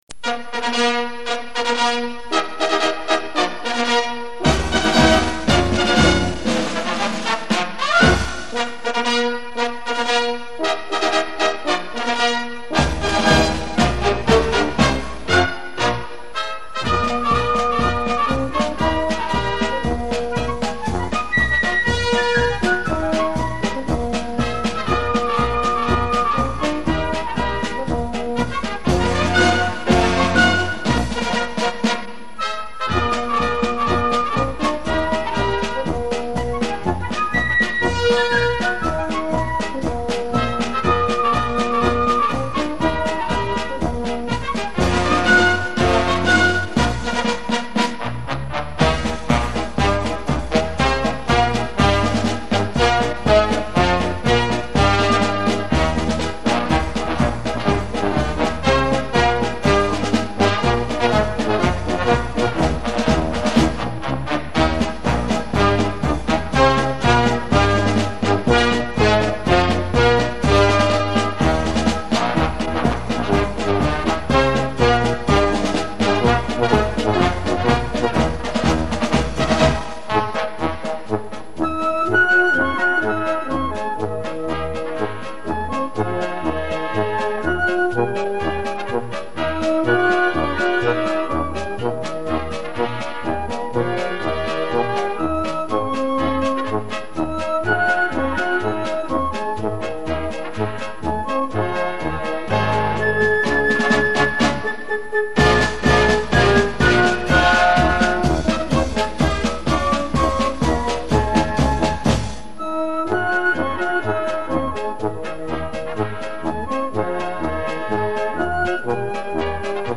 进行曲精选